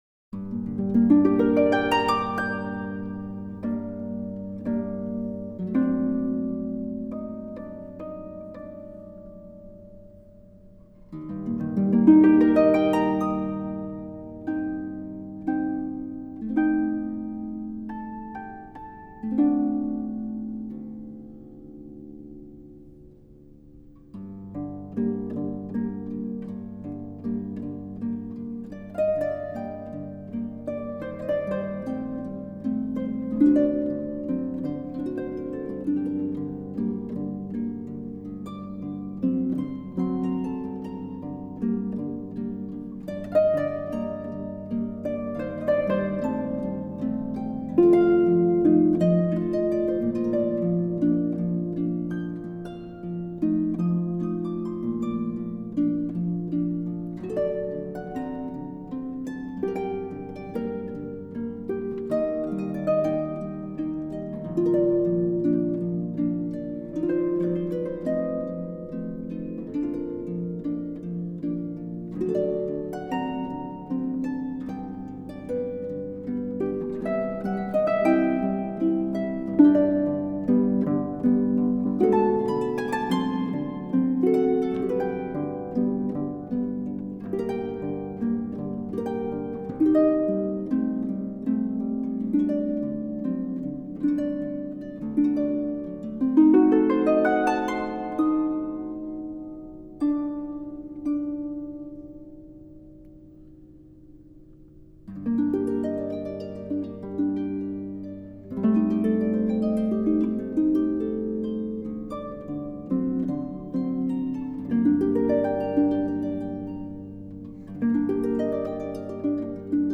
Heart-felt and uplifting